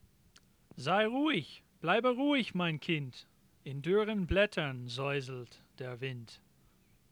Ein Gedicht